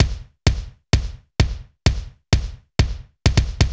BAL Beat - Mix 13.wav